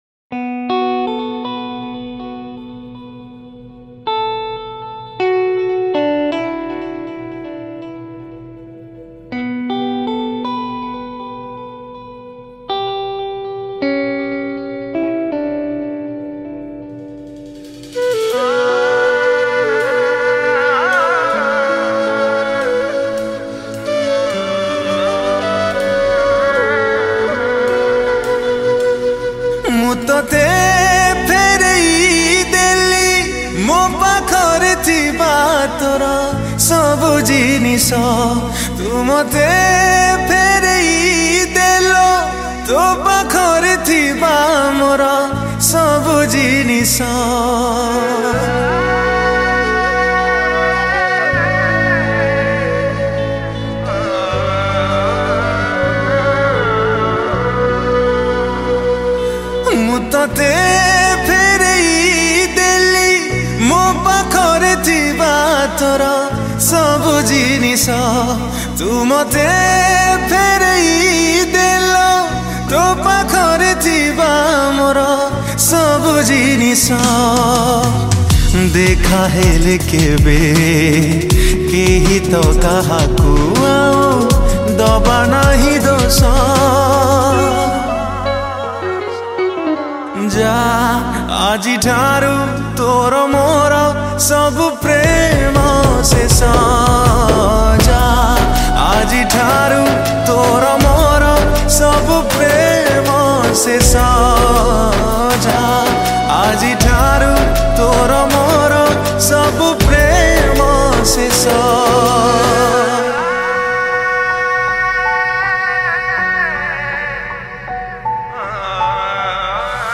Recorded At: Sidharth Studio